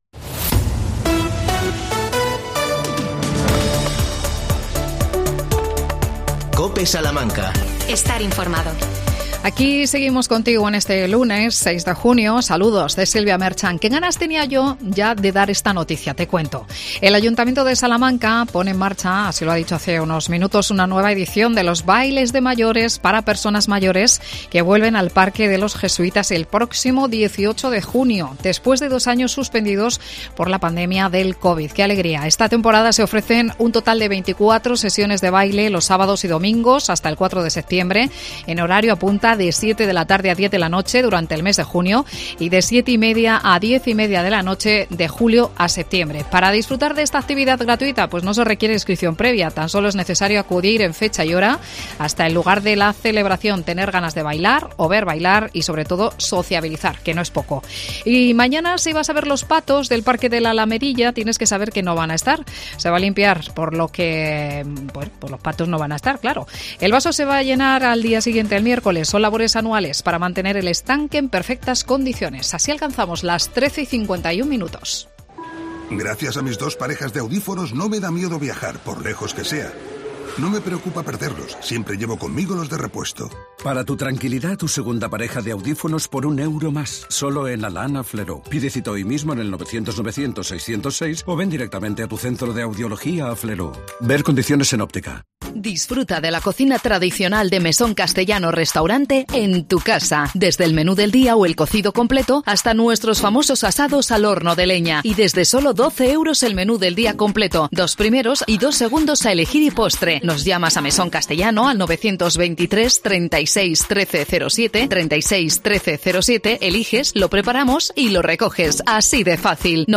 Entrevistamos al pediátra